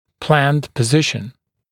[plænd pə’zɪʃn][плэнд пэ’зишн]запланированное положение